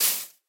grass1.ogg